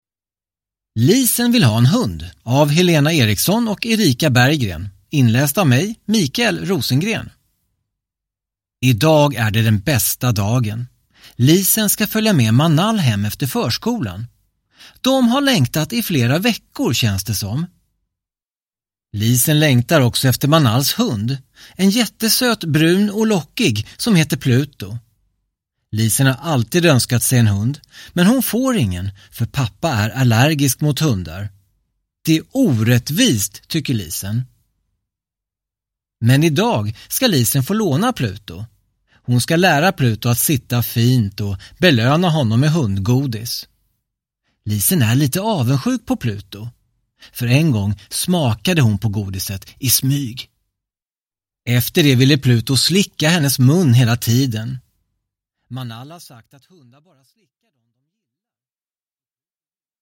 Lisen vill ha en hund (ljudbok) av Helena Eriksson